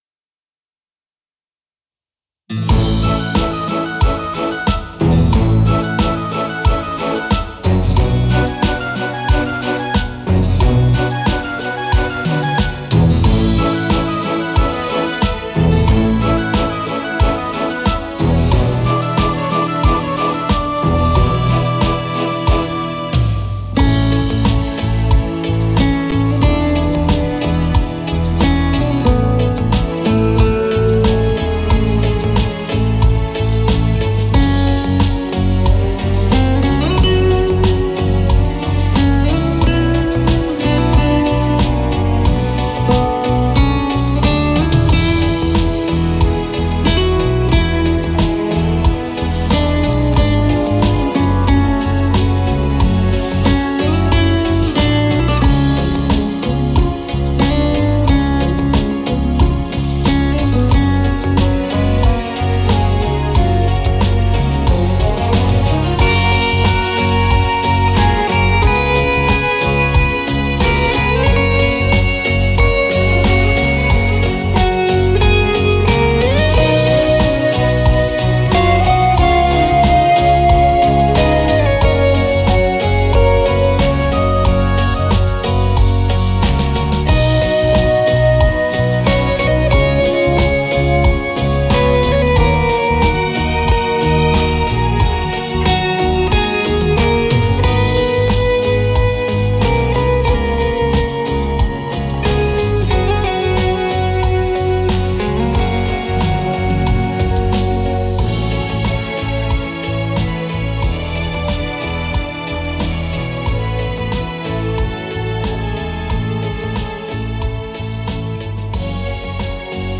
Moderato
12 brani per chitarra solista composti dal M?